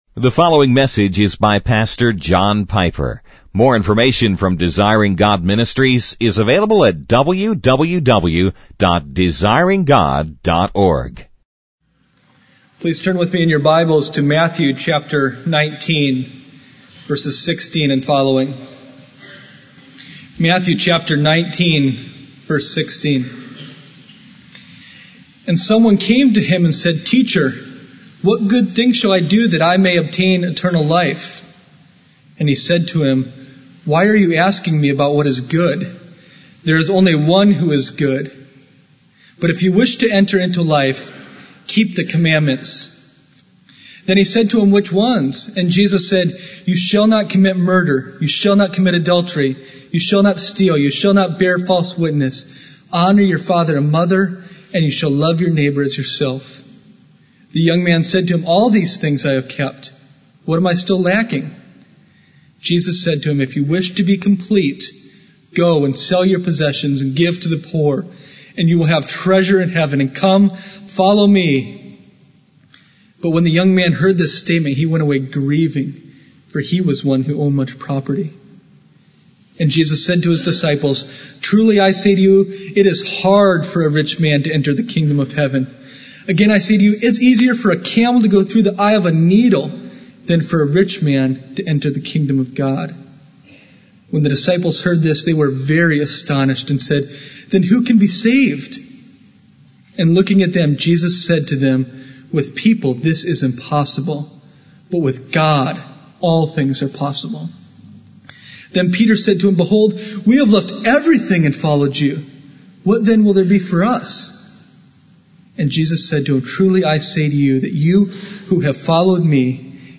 In this sermon, the speaker focuses on the topic of salvation and the desire for eternal life. The text being discussed is Matthew 19:16, where a man asks Jesus what good thing he must do to have eternal life.